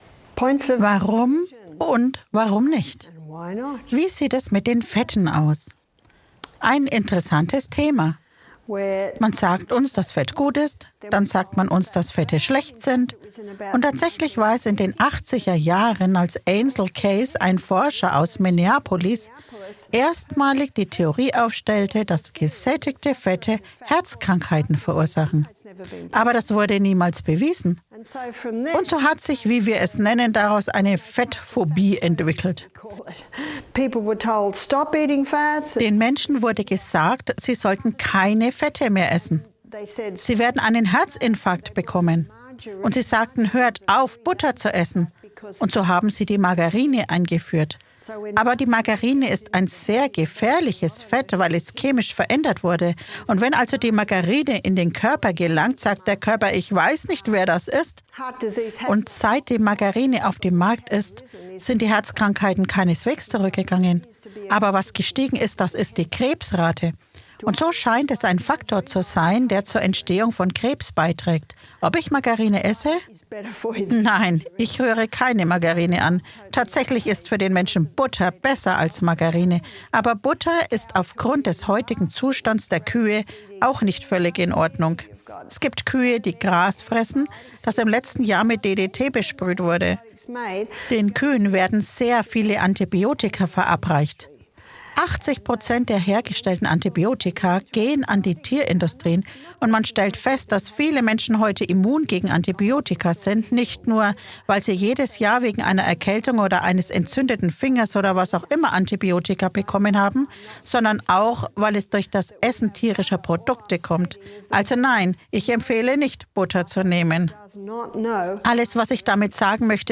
In diesem aufschlussreichen Vortrag wird die Welt der Fette beleuchtet. Von der Margarinephobie bis zu den Vorzügen von Oliven- und Kokosnussöl gibt der Referent spannende Einblicke in die Ernährungsthemen.